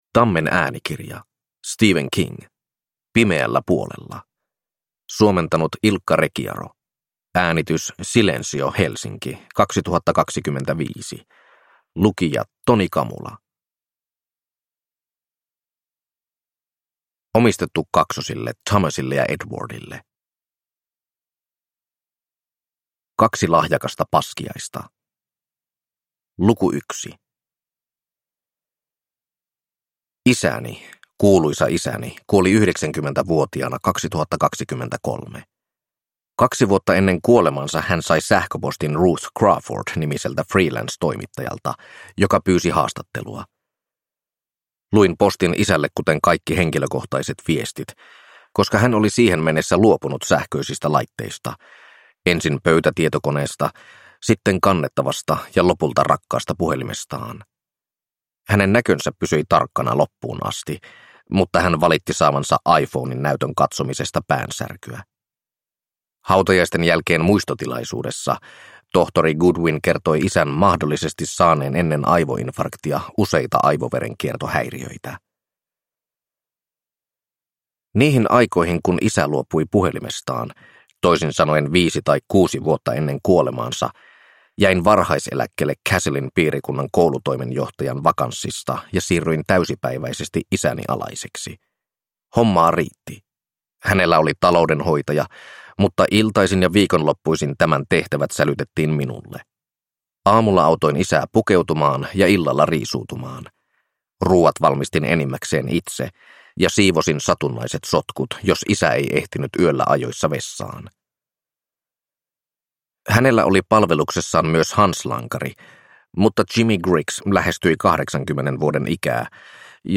Pimeällä puolella – Ljudbok